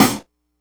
snare.wav